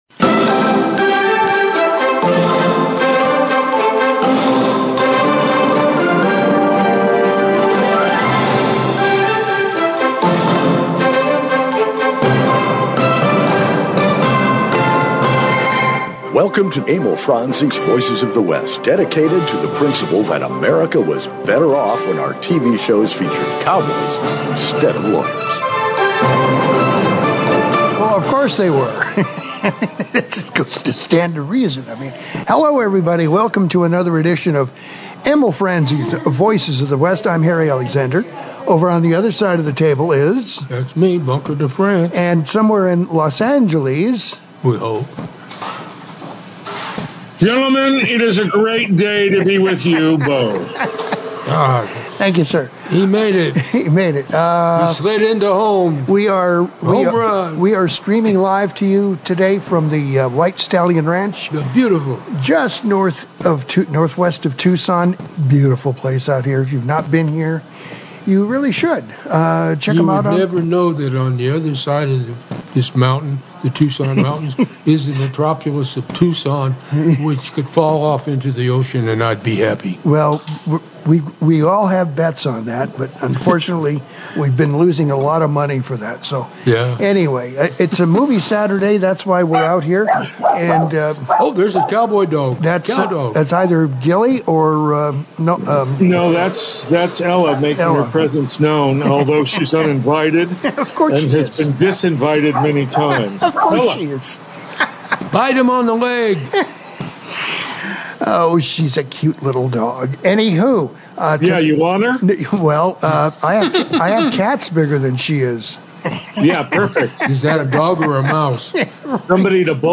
It’s our Movie Saturday program from the White Stallion Ranch! Our topic is the Westerns that changed the Genre.